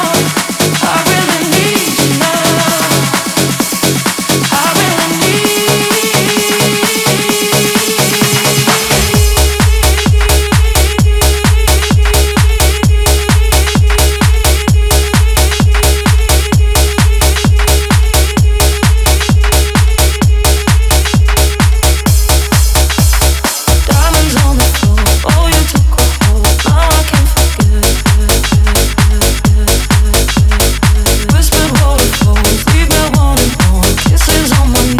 Жанр: Хаус
# House